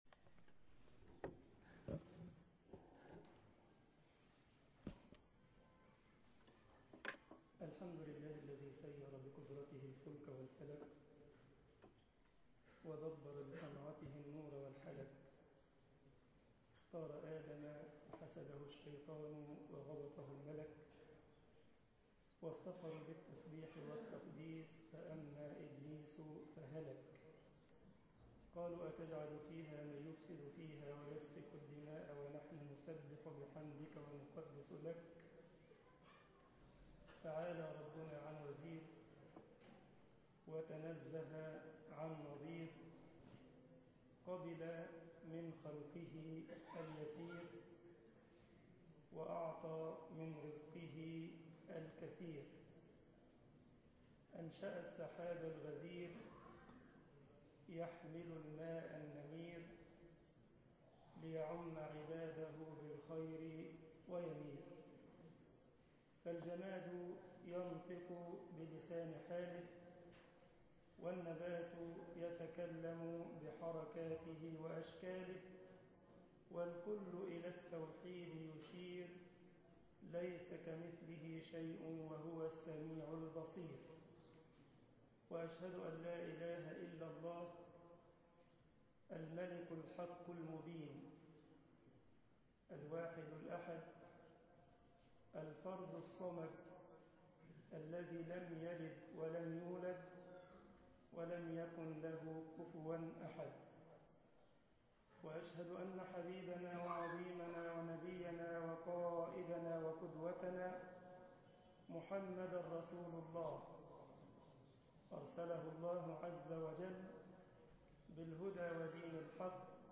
مسجد نور الاسلام ـ عين شمس محاضرة